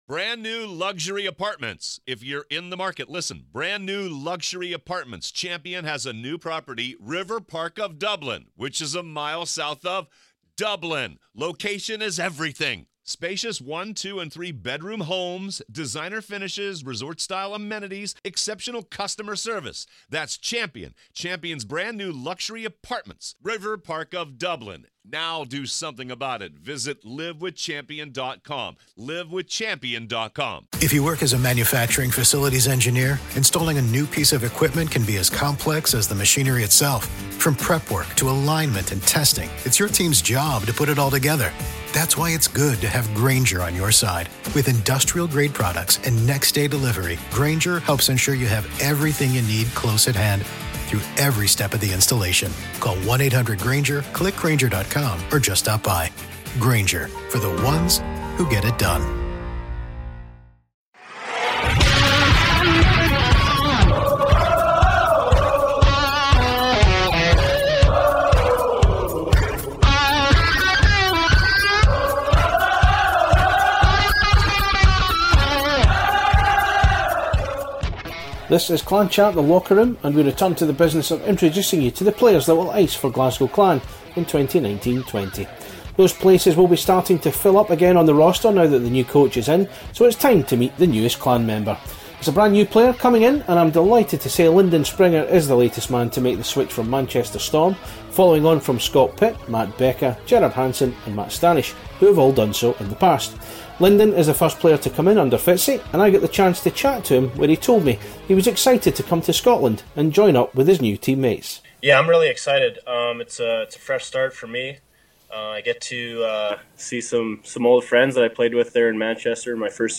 We caught up with him for his first interview as a Clan player.